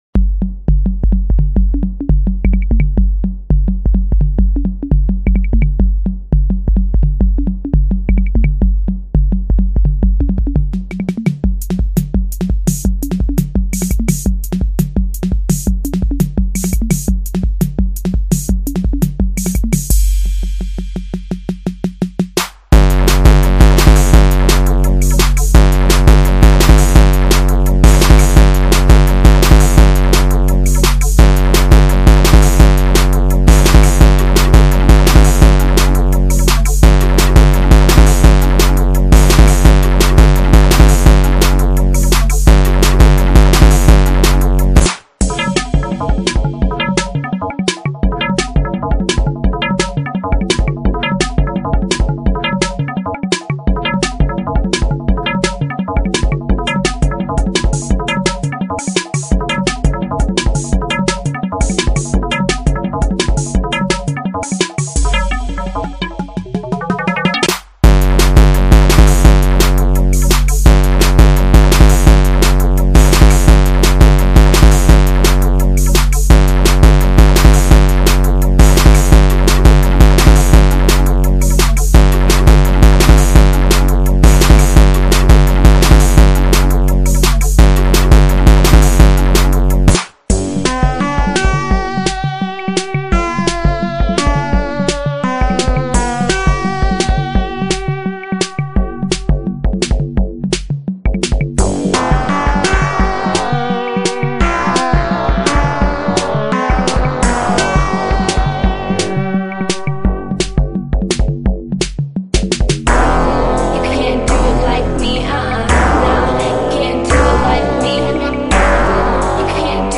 This page contains an audio file that is either very loud or has high frequencies.